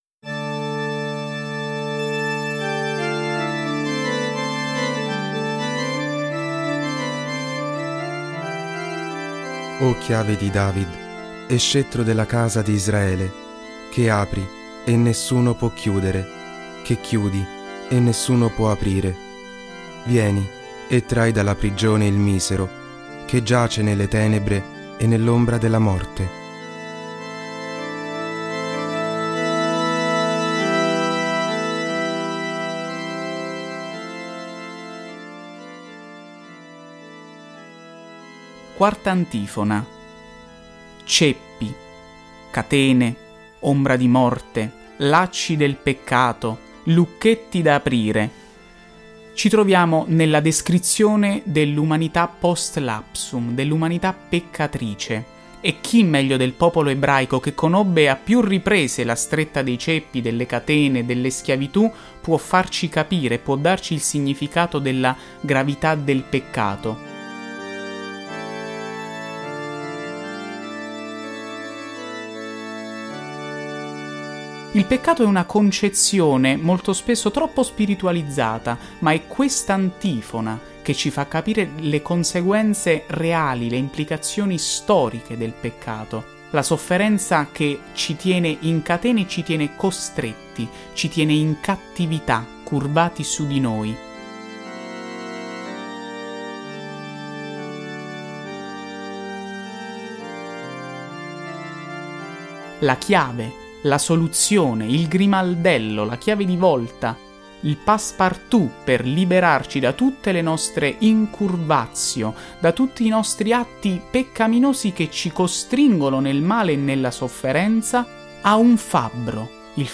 Le esecuzioni delle Antifone O dell'"Ensemble dei Fiorentini"
O-CLAVIS-DAVID-CON-EFFETTI.mp3